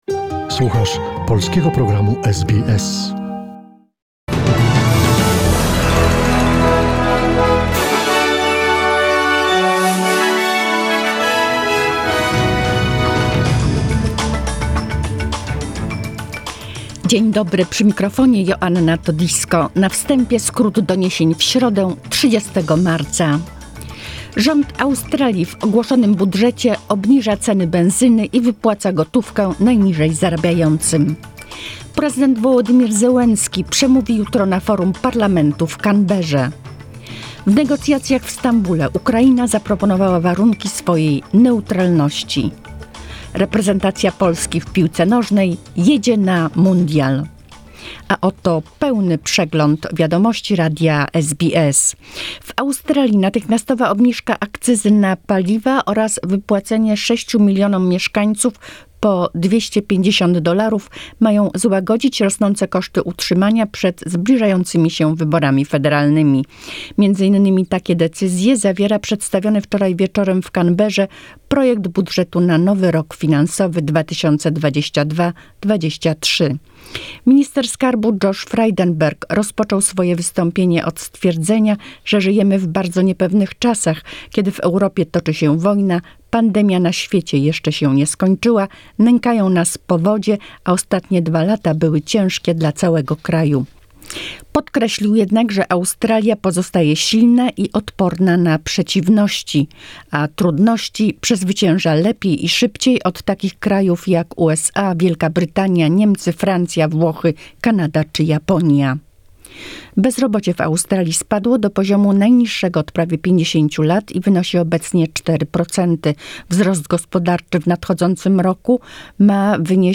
SBS News in Polish, 30 March 2022